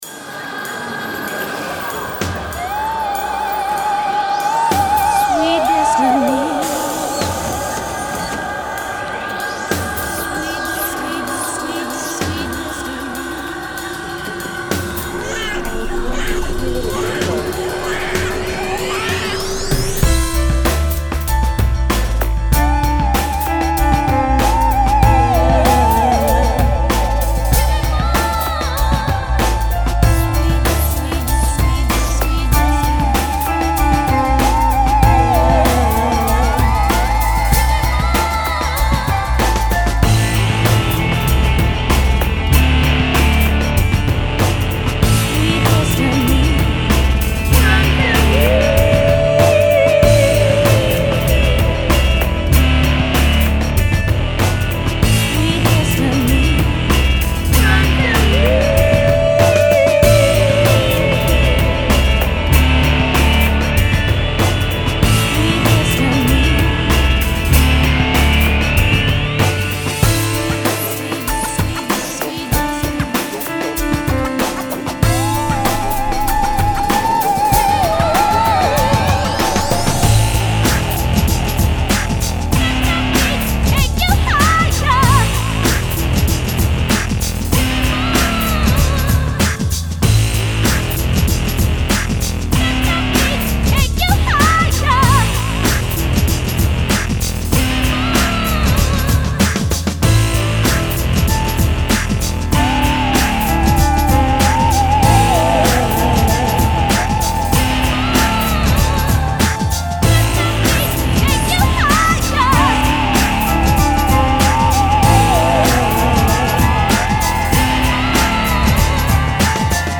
Electronica, Progressive House, House and Breaks